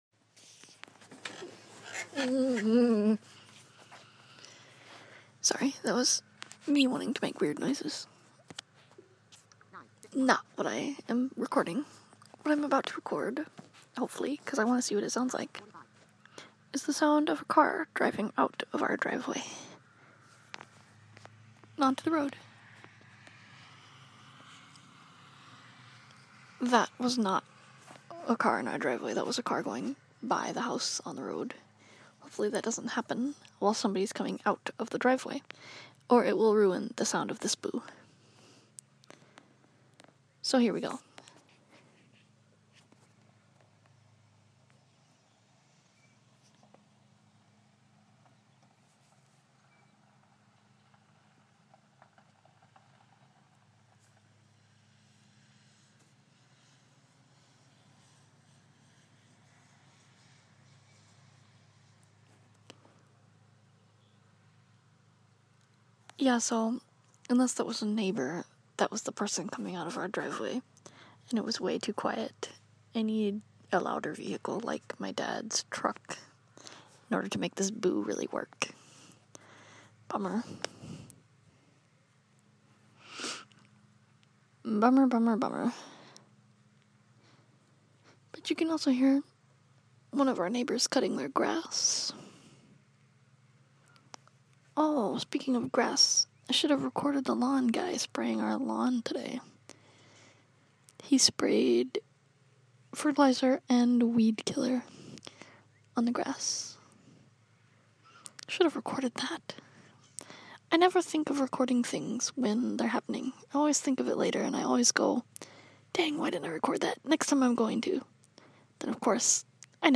trying and failing epically at capturing some of the awesome sounds outside my window.